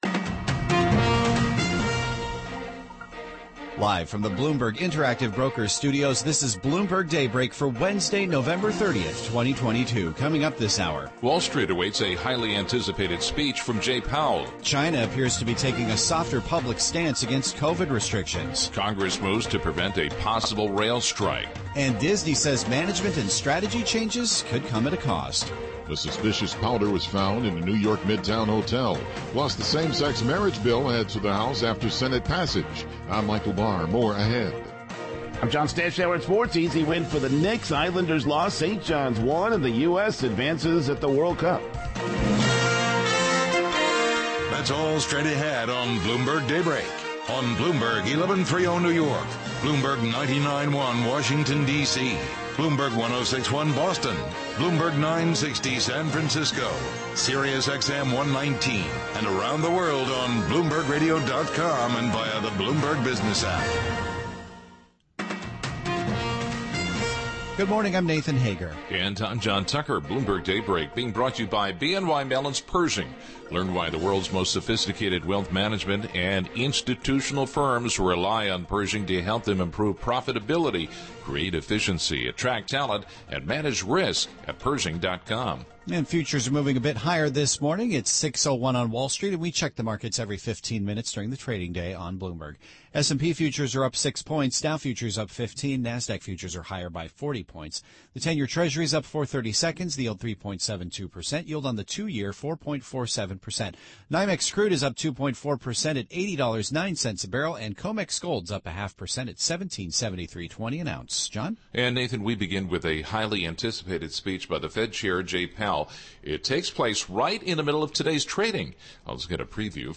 Bloomberg Daybreak: November 30, 2022 - Hour 2 (Radio)